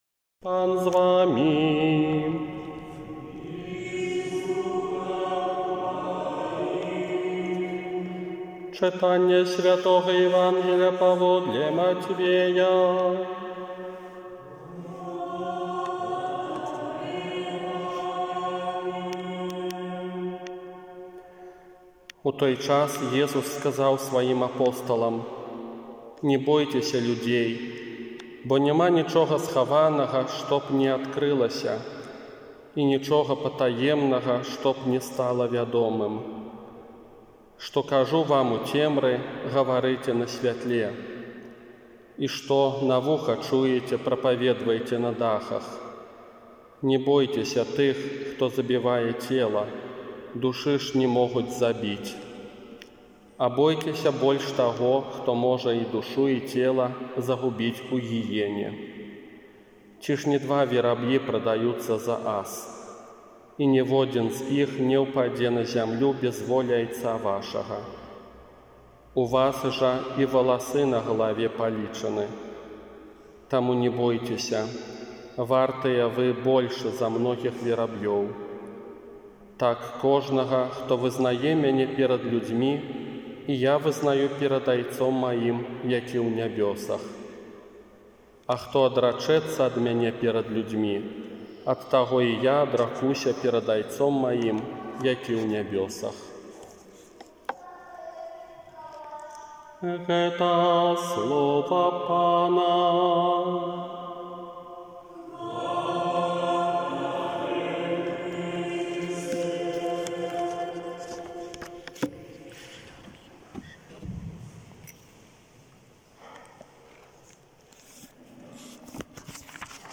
Казанне на дванаццатую звычайную нядзелю 21 чэрвеня 2020 года
Праўда_казанне.m4a